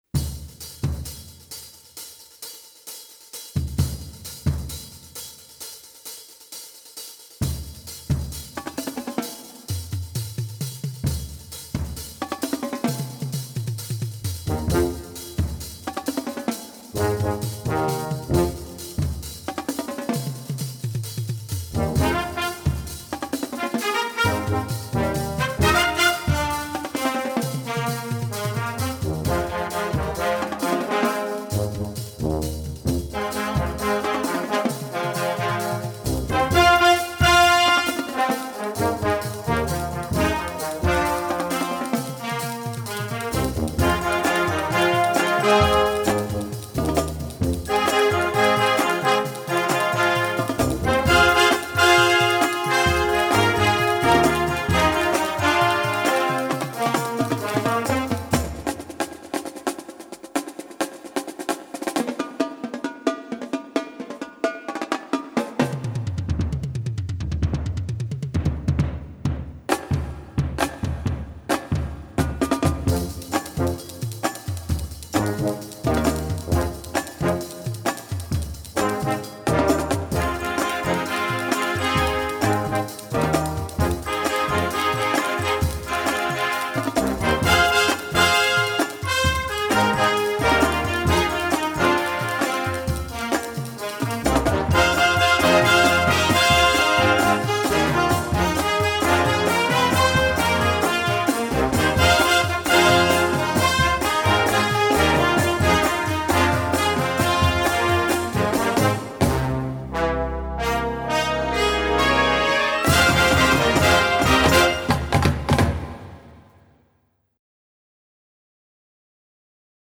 SNARE DRUM 8 1
QUAD TOMS 4 1
MULTIPLE BASS DRUMS 4 1